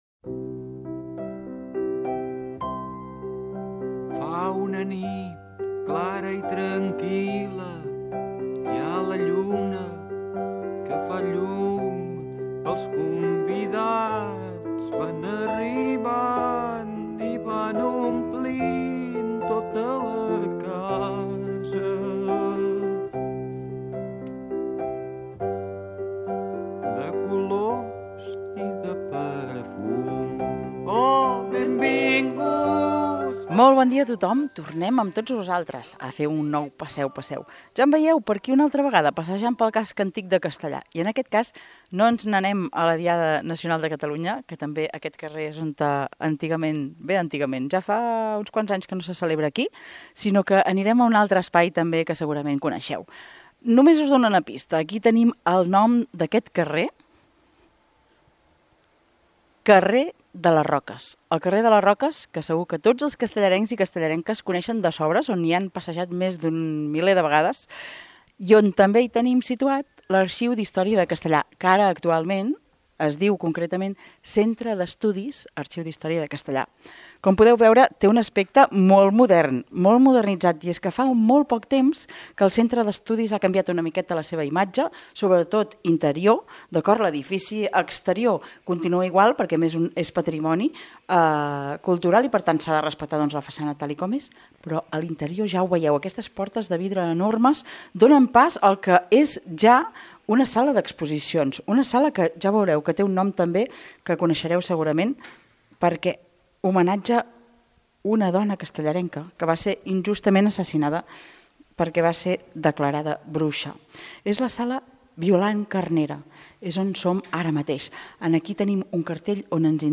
Amb elles, s'iniciarà una conversa d'una mitja hora durant la qual es tractaran diversos temes. L'entorn, en aquestes xerrades, serà un personatge més.